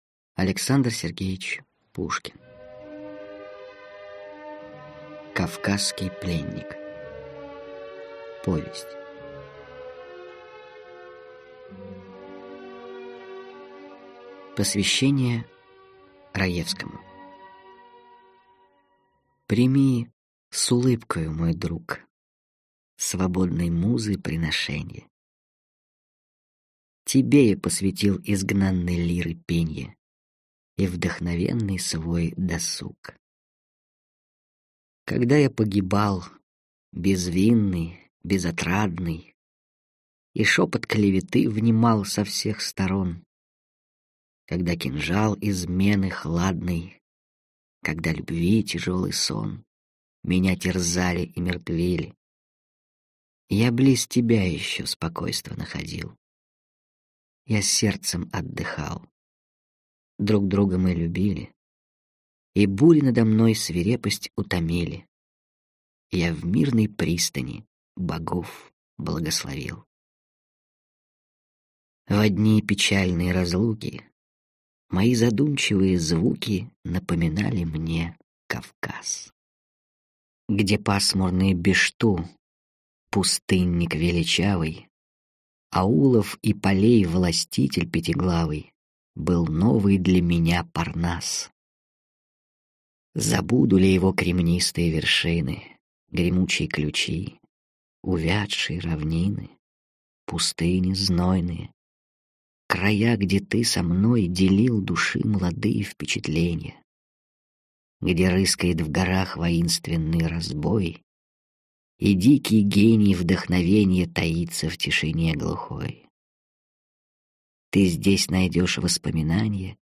Аудиокнига Кавказский пленник | Библиотека аудиокниг